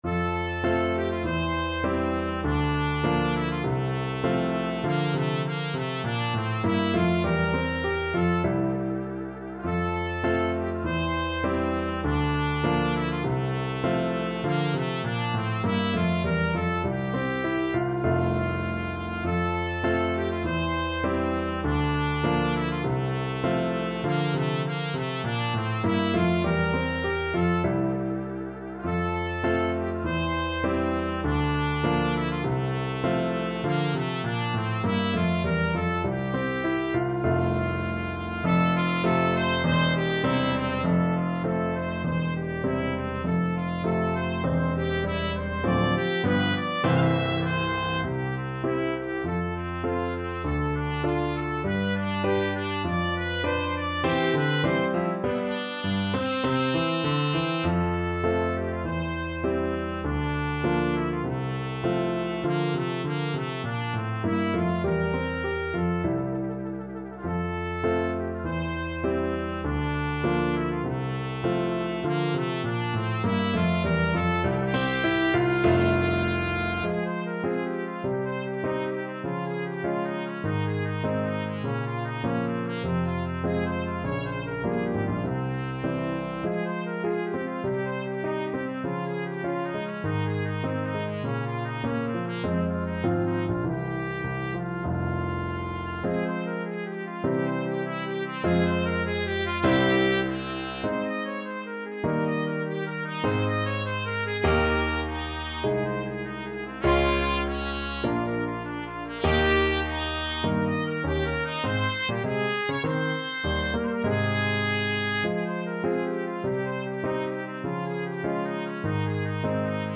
2/2 (View more 2/2 Music)
~ = 100 Allegretto
Classical (View more Classical Trumpet Music)